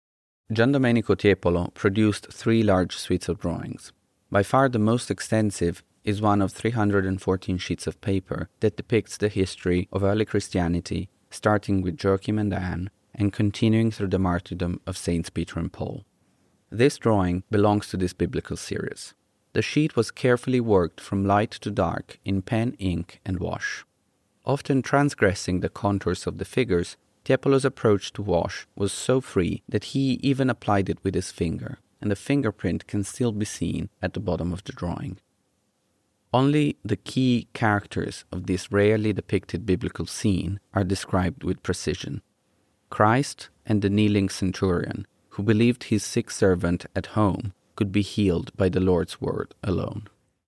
Each object is accompanied by an audio recording of the label text.